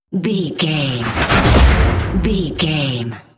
Dramatic chopper to hit 650
Sound Effects
dark
intense
tension
woosh to hit